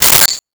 Sword Hit 05
Sword Hit 05.wav